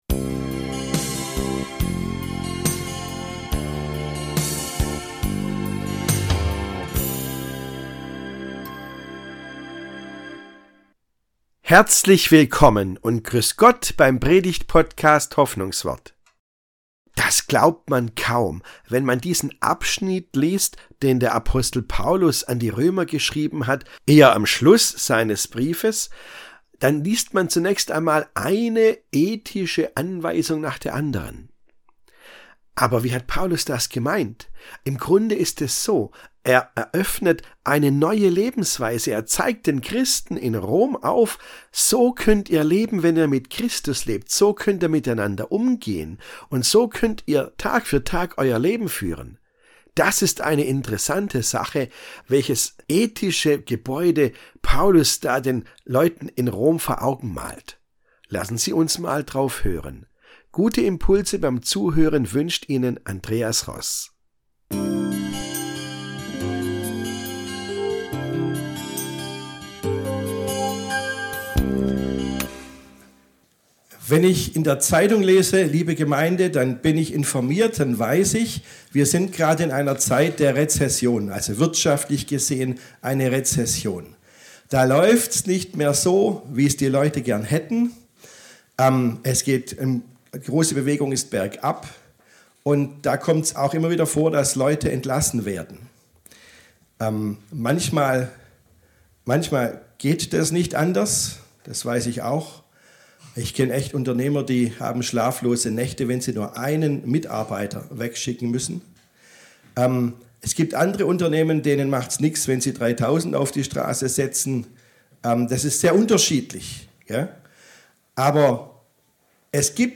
Liebe statt höher-schneller-weiter ~ Hoffnungswort - Predigten